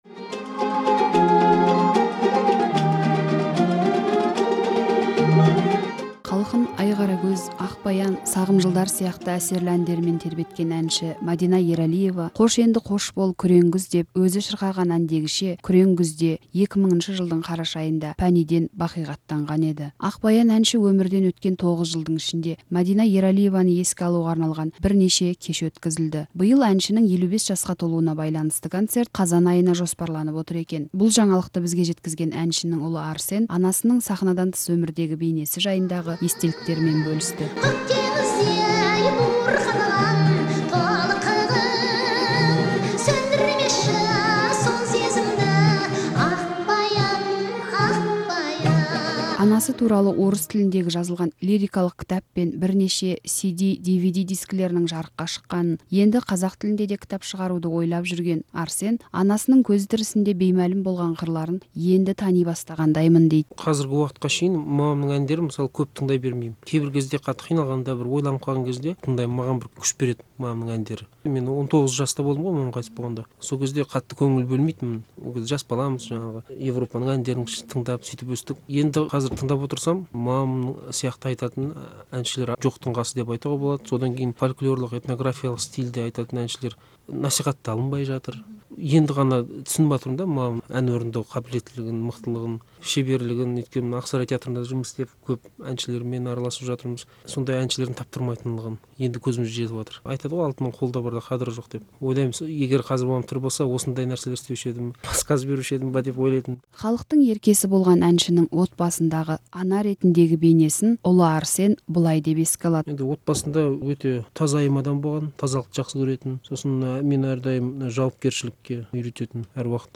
сұхбаты